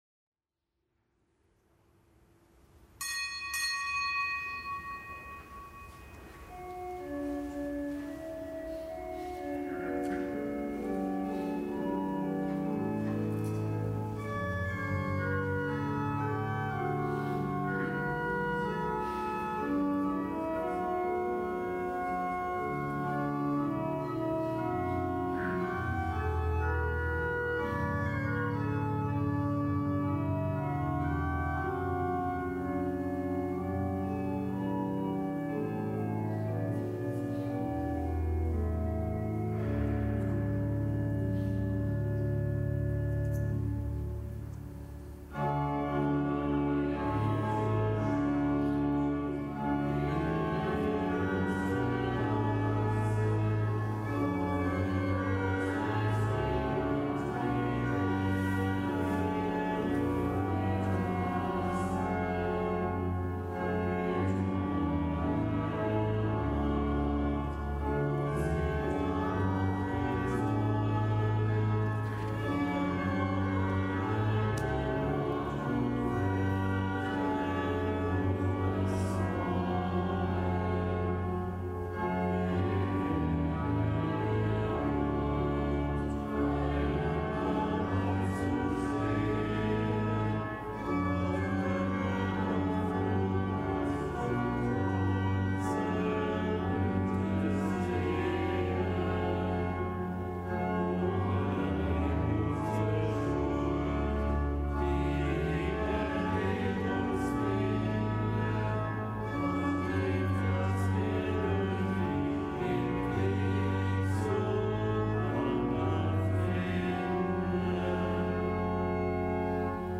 Kapitelsmesse aus dem Kölner Dom am Montag der zweiten Fastenwoche. Zelebrant: Weihbischof Rolf Steinhäuser.